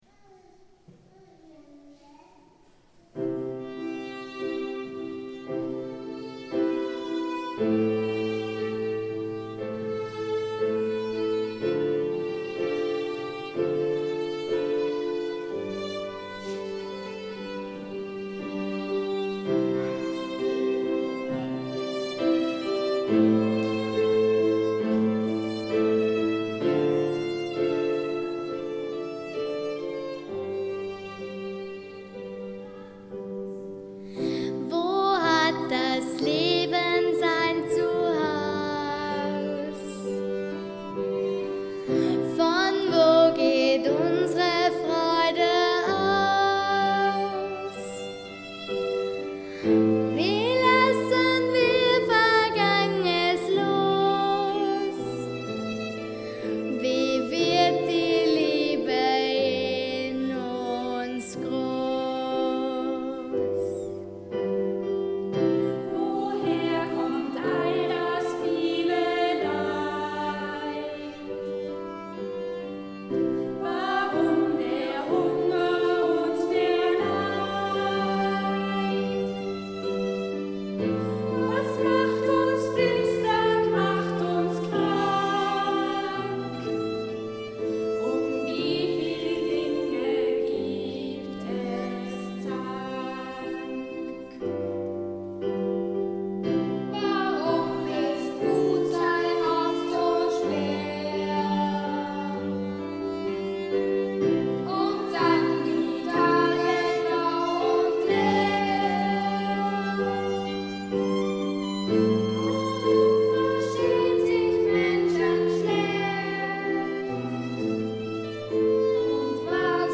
Ein Musical-Projekt von Kindern und Erwachsenen der Pfarre Starchant
Am Klavier
An der Violine
Bartimäus in der Wotrubakirche am 20. April 2012
Zudem gibt es einige choreografierte Passagen (also mit Tanz) und viel Mehrstimmiges. Begleitet wird das Ganze am Klavier und von einer professionellen Geigerin.
Das Singspiel "Blinder Bruder Bartimäus" ist ein vielfach mehrstimmiges, sehr berührendes Stück über die Heilung des Bartimäus, das aber so "erzählt" und "erfahrbar" gemacht wird, dass alle - SängerInnen wie Publikum - das Sehend-Werden als eigene Erfahrung erspüren können.
Bartimaeus_Wotruba_alleSpuren_mix.mp3